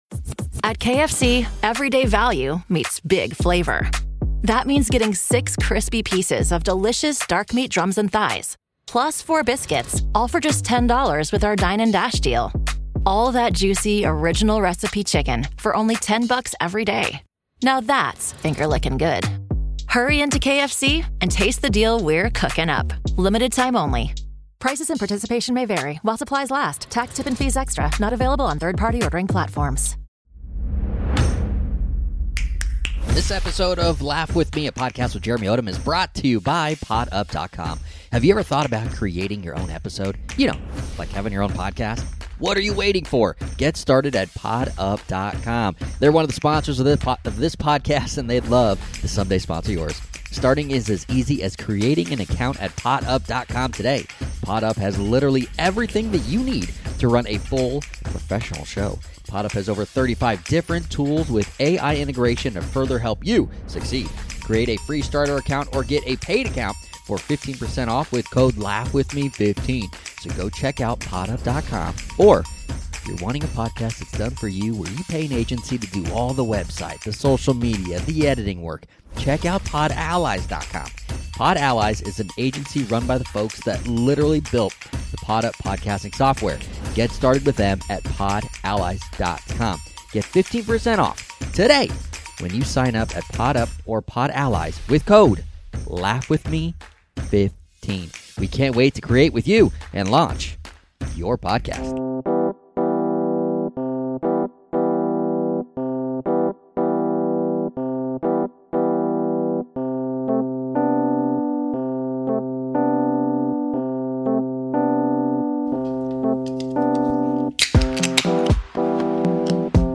Comedy, Stand-up